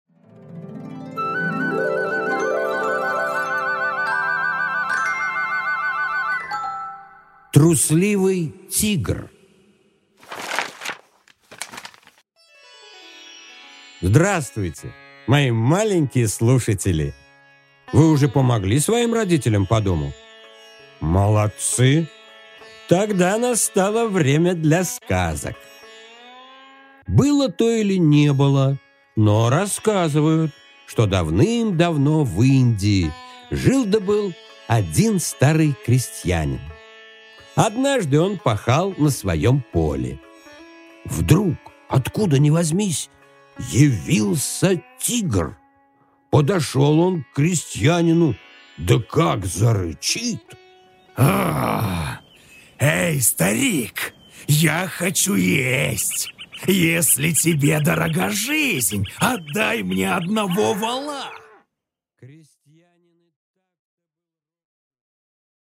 Аудиокнига Трусливый тигр